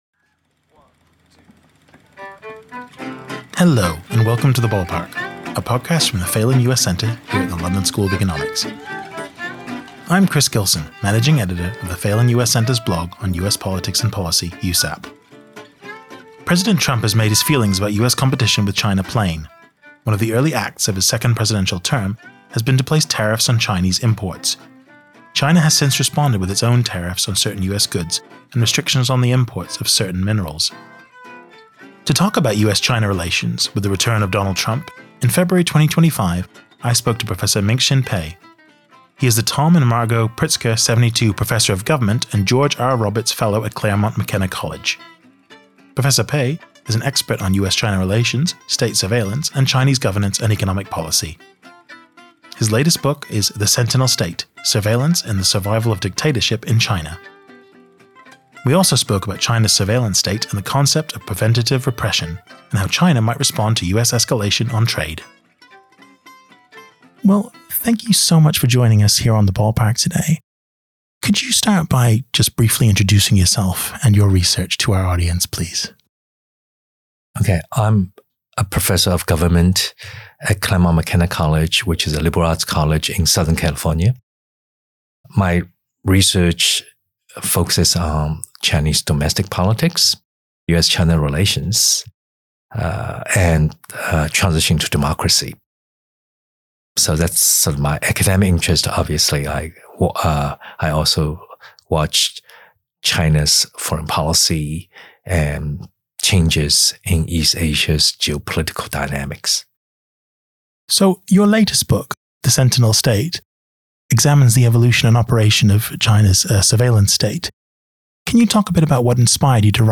To talk about US-China relations with the return of Donald Trump, in February 2025 the Phelan US Centre spoke to Professor Minxin Pei, the Tom and Margot Pritzker '72 Professor of Government and George R. Roberts Fellow at Claremont McKenna College. They also spoke about China’s surveillance state and the concept of preventative repression, and how China might respond to US escalation on trade.